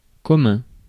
Ääntäminen
France: IPA: [kɔ.mɛ̃]